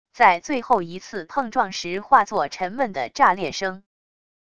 在最后一次碰撞时化作沉闷的炸裂声wav音频